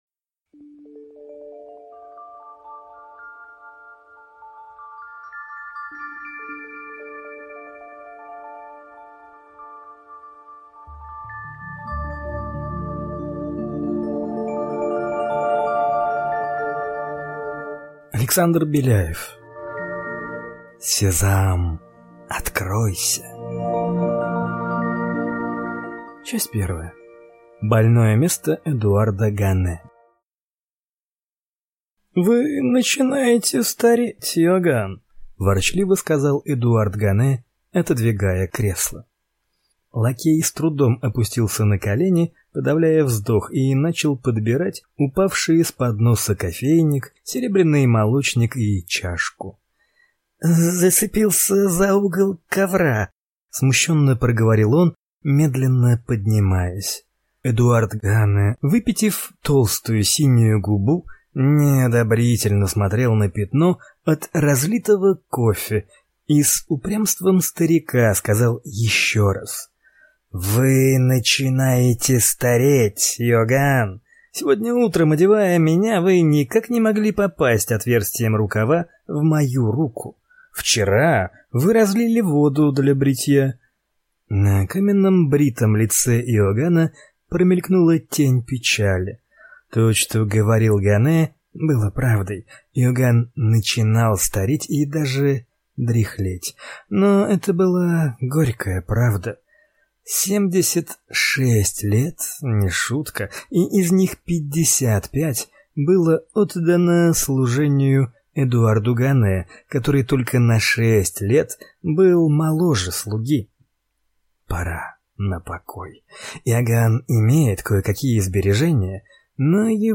Аудиокнига Сезам, откройся!!!
Прослушать и бесплатно скачать фрагмент аудиокниги